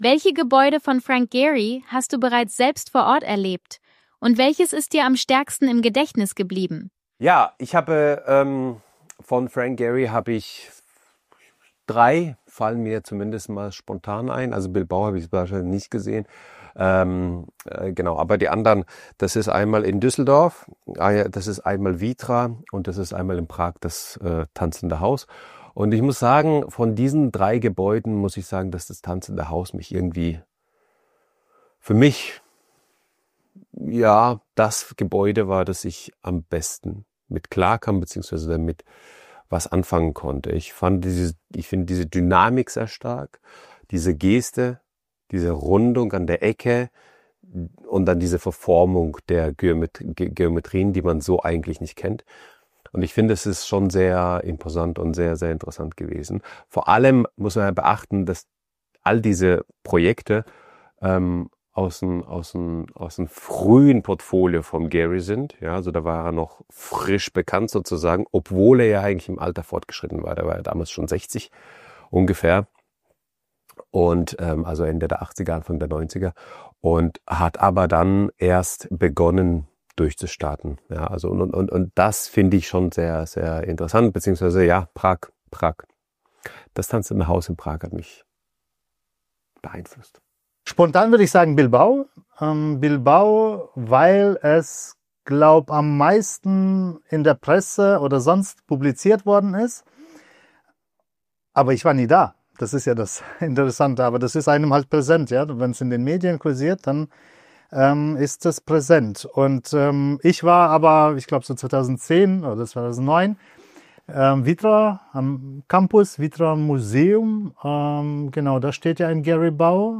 043 Was bleibt von Frank Gehry? Zwei Architekten ordnen sein Werk ein